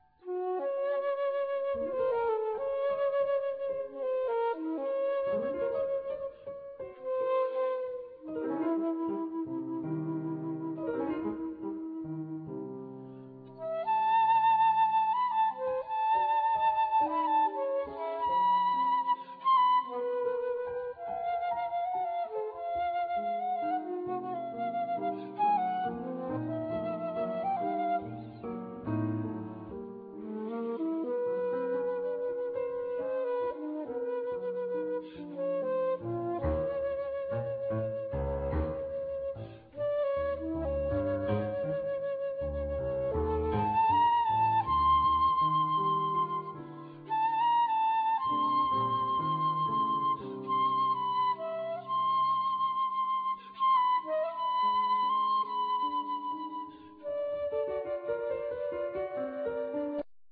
Flute
Piano
Violin
Bass
Percussion